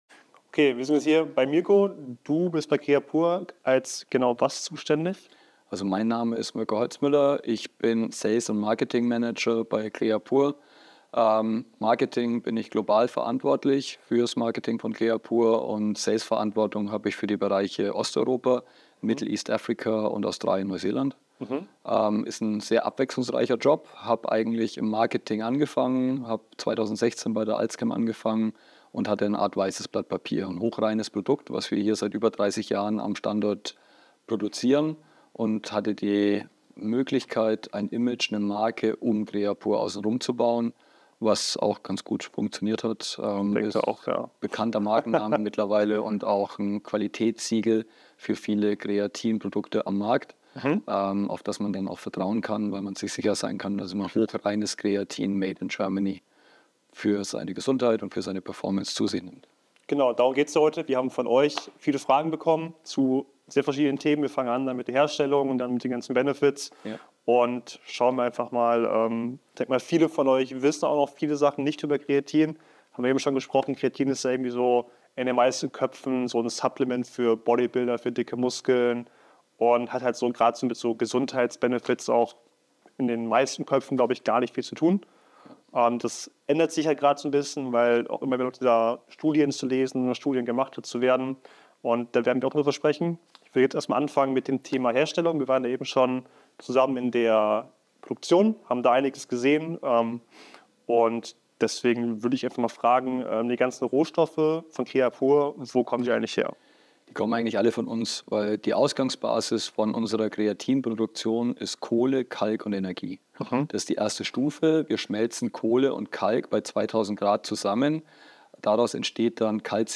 In dieser Folge haben wir einen Experten zu Gast, der Klarheit schafft! Wir sprechen über die Wirkung von Kreatin auf Muskelaufbau, Ausdauer und kognitive Leistung. Außerdem klären wir, ob es Nebenwirkungen gibt, wie die optimale Dosierung aussieht und für wen Kreatin besonders sinnvoll ist.
Experten_Interview_u--ber_Kreatin_(Wirkung-_Vorteile-_Nebenwirkungen_und_Studien).mp3